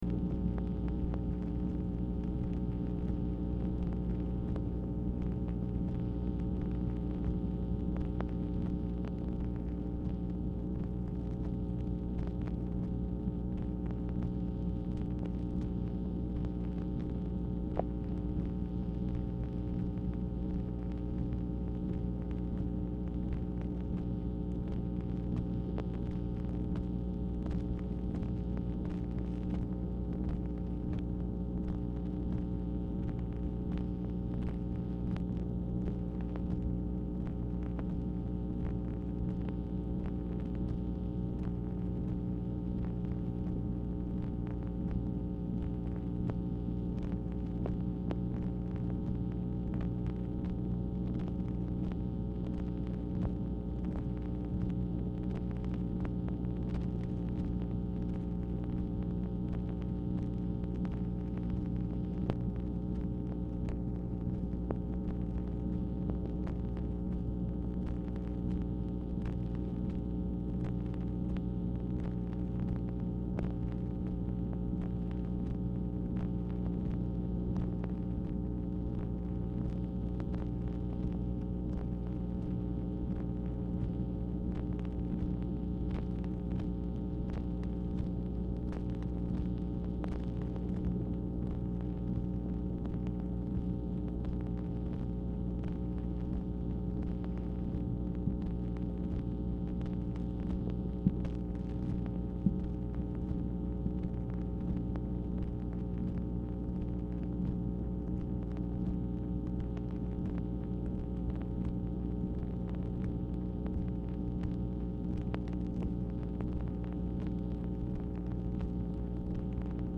Telephone conversation # 10793, sound recording, MACHINE NOISE, 9/17/1966, time unknown | Discover LBJ
Format Dictation belt